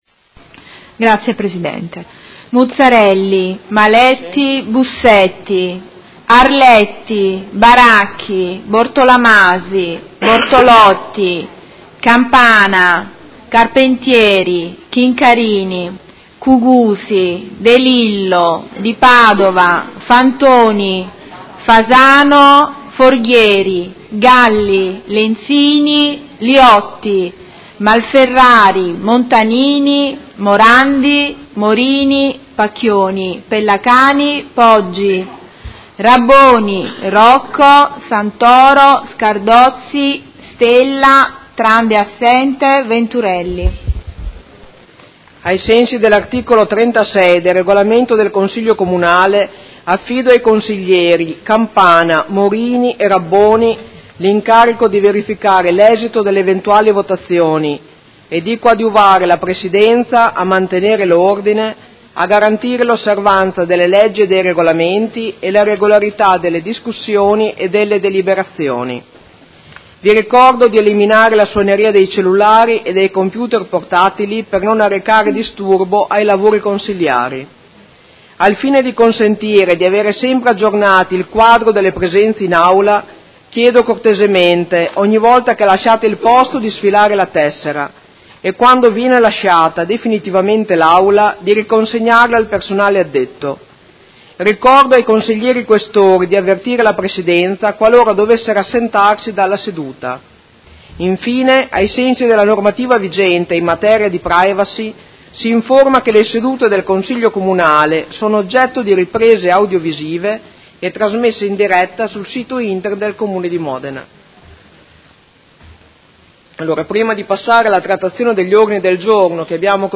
Segretaria — Sito Audio Consiglio Comunale
Seduta del 07/02/2019 Appello.